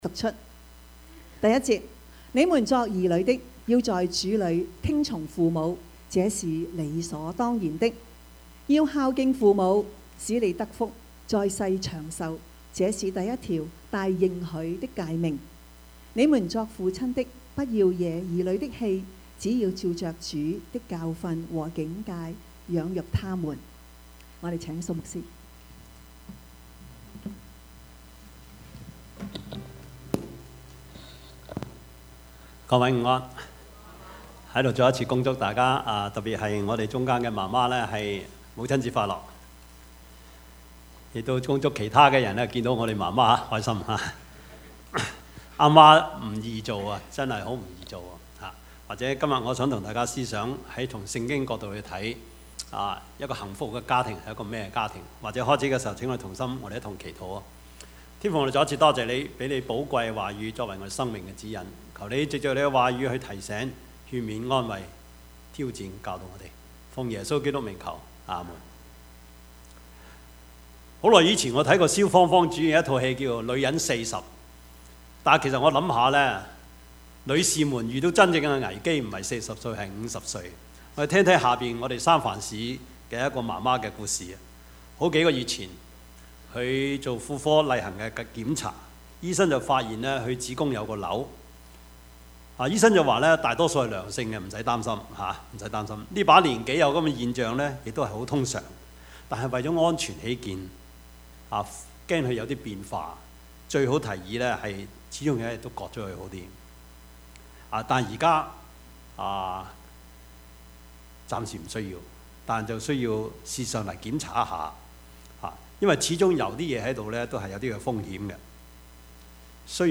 Service Type: 主日崇拜
Topics: 主日證道 « 面對誹謗 不是修補, 乃是重建 »